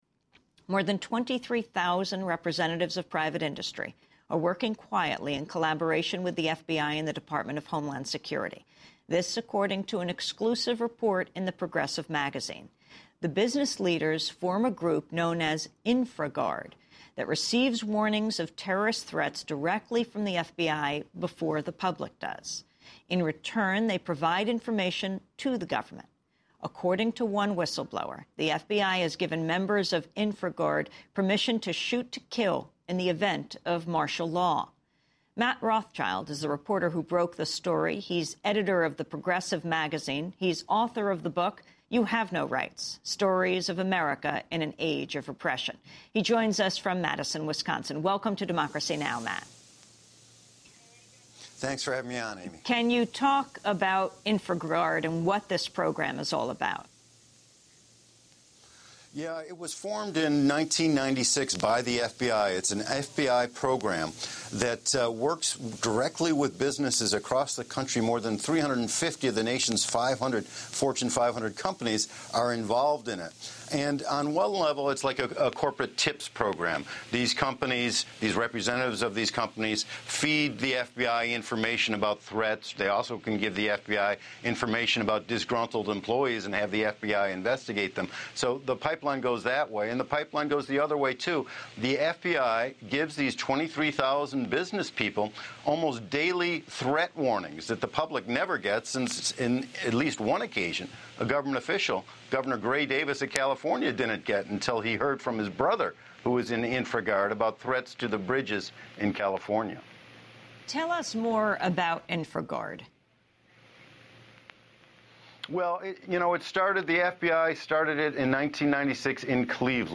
Tags: Historical Top 10 Censored News Stories 2009 Censored News Media News Report